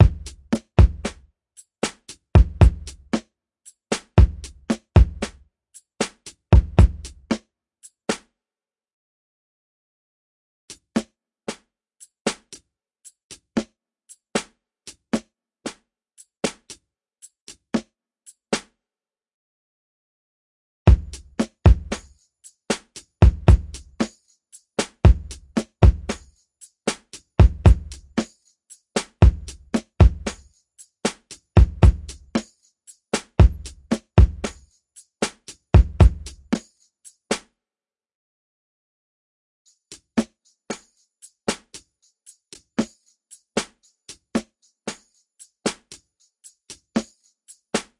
描述：创建于Reason 7
标签： 循环 节奏 普罗佩勒黑兹 原因
声道立体声